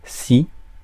Ääntäminen
IPA: [si]